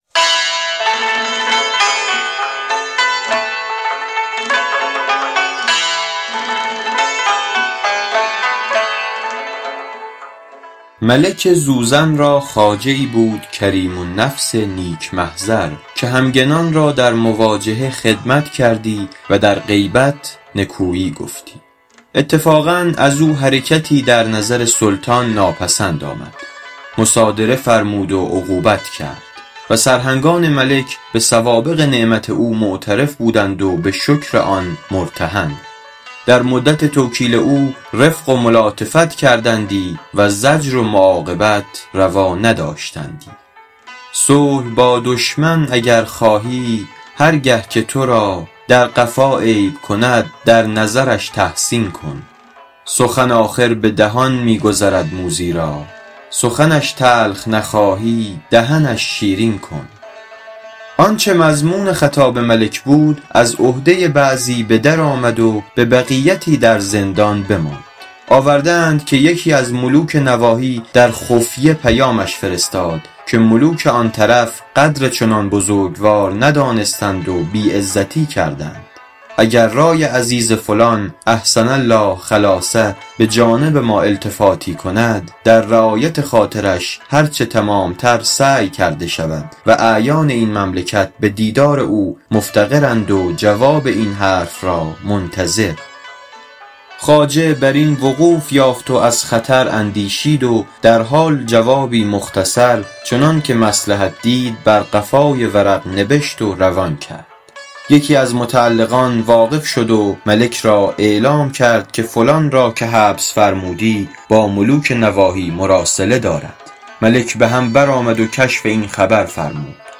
گنجور » نمایش خوانش